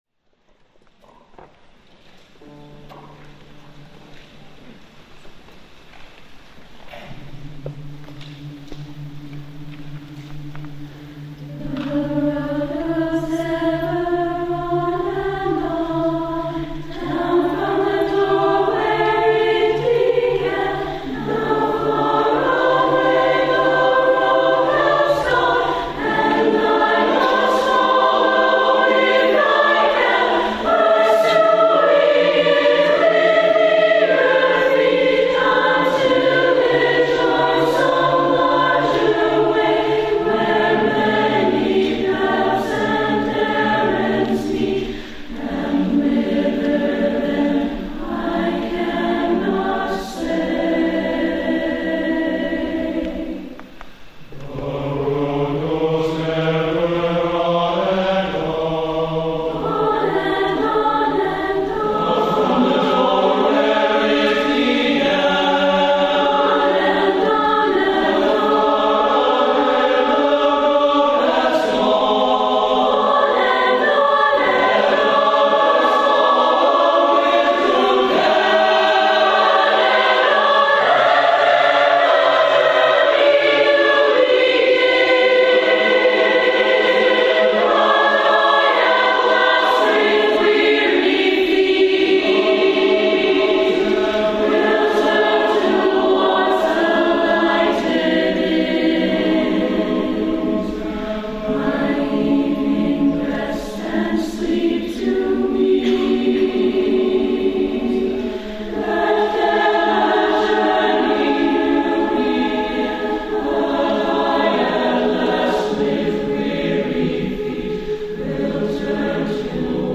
for SATB Chorus (2006)
conductor.